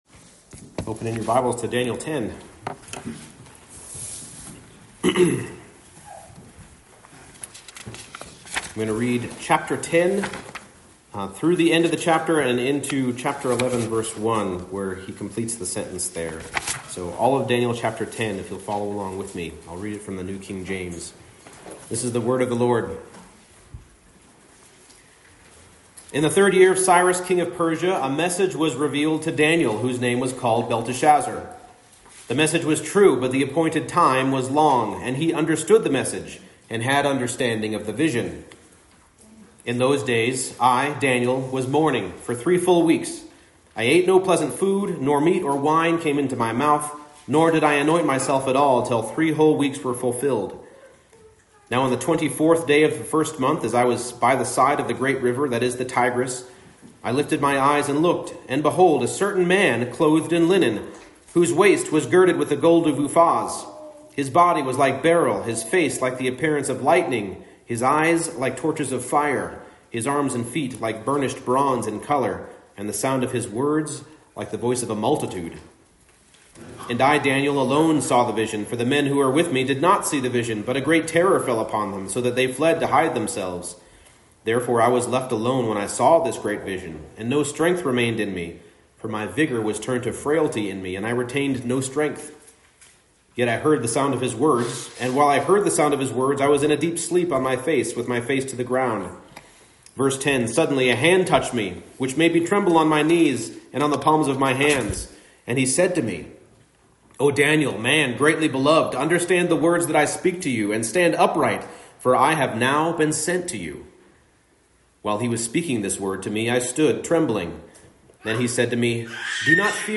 Daniel 10 Service Type: Morning Service God responds with spiritual violence to fervent and humble prayer.